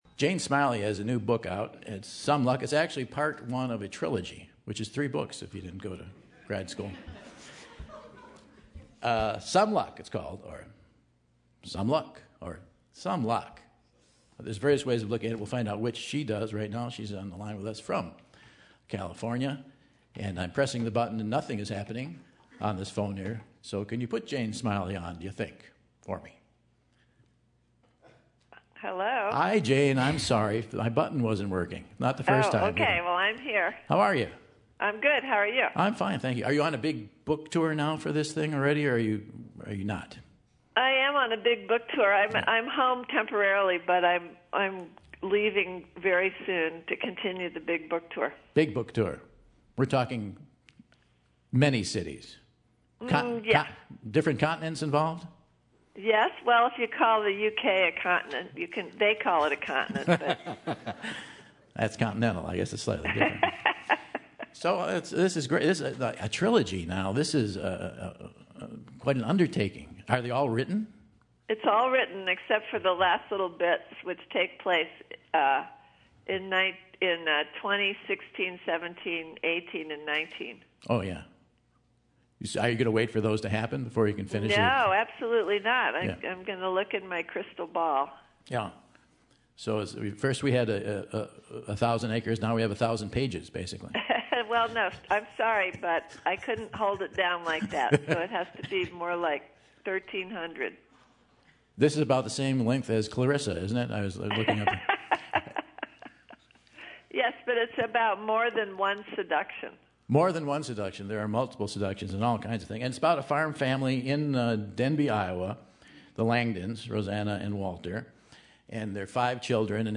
This week she and Michael chat about the new book, Some Luck and more!